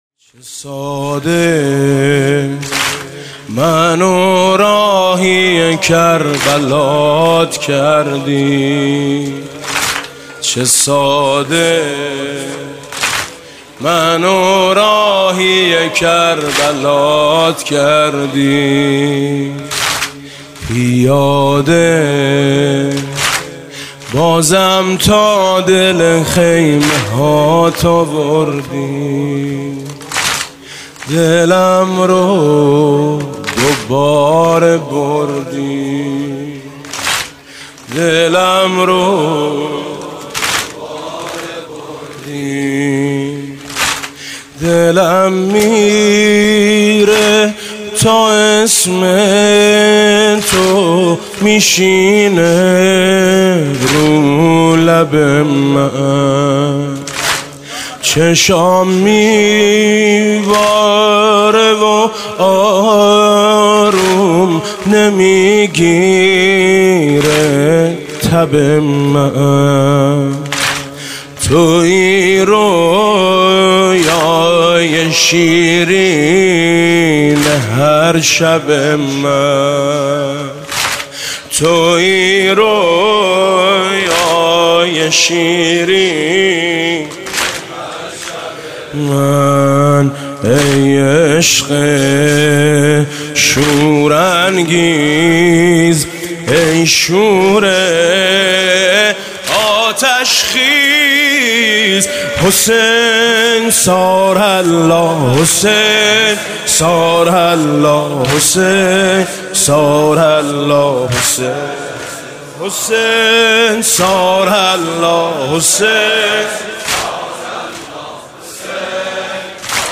شب دوم اربعین ۹۸
music-icon واحد: چه ساده، منو راهی کربلات کردی حاج میثم مطیعی